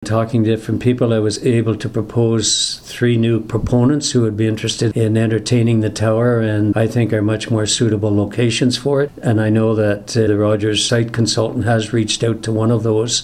At Arnprior Council Monday, Mayor Walter Stack updated the community on discussions with (and about) Rogers Communications- which has apparently had its eyes on a piece of property at Edward and William Streets for the past several years.